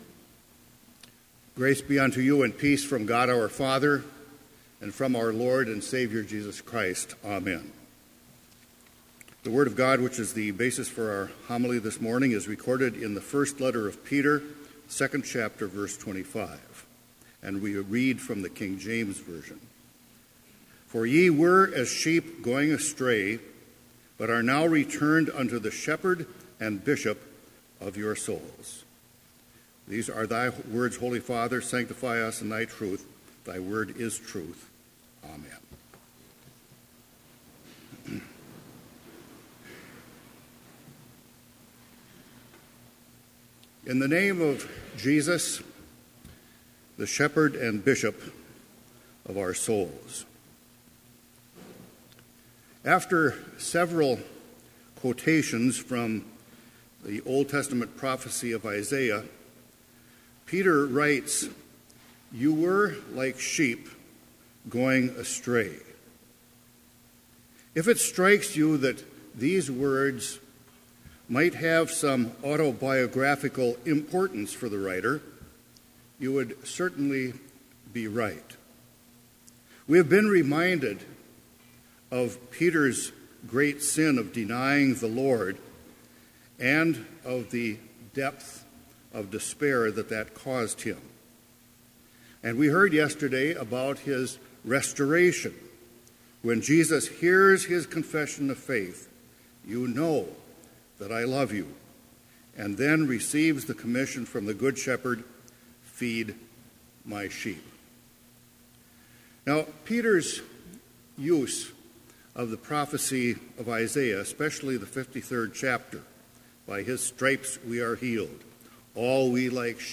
Complete Service
• Hymn 369, vv. 1-4, O Bless the Lord, My Soul
This Chapel Service was held in Trinity Chapel at Bethany Lutheran College on Tuesday, May 2, 2017, at 10 a.m. Page and hymn numbers are from the Evangelical Lutheran Hymnary.